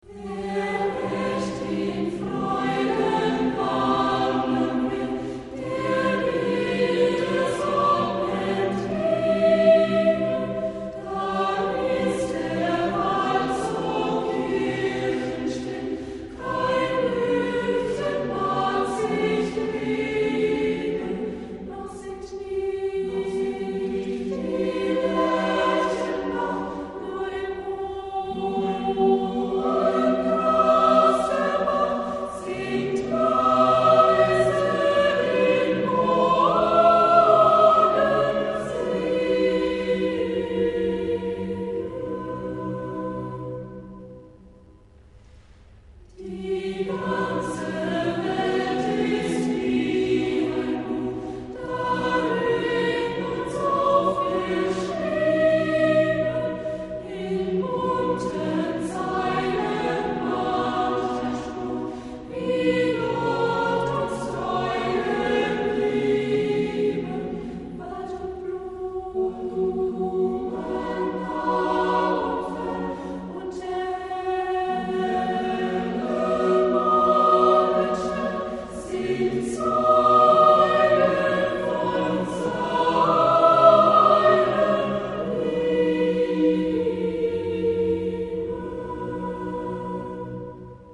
Chorbuch mit CD und Kopierrecht